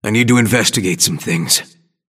Grey Talon voice line - I need to investigate some things.